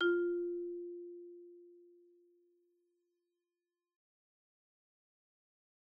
Marimba_hit_Outrigger_F3_loud_01.wav